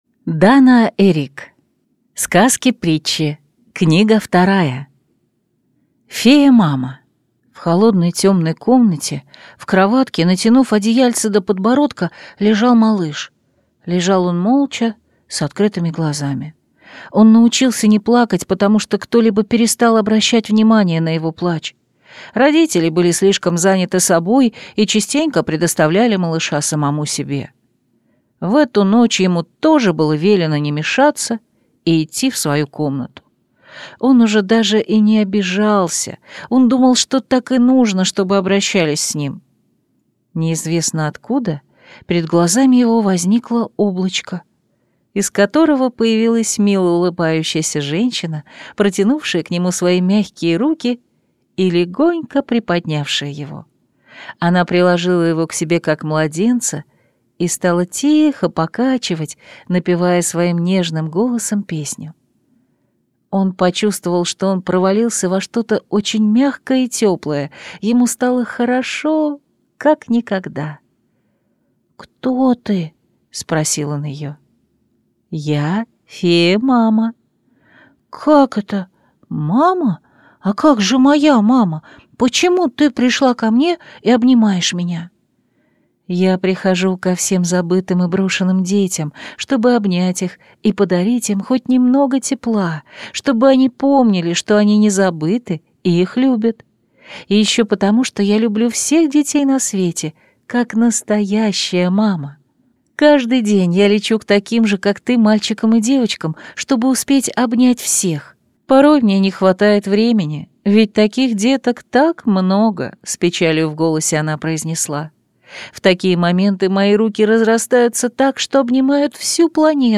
Аудиокнига Сказки-притчи. Книга 2 | Библиотека аудиокниг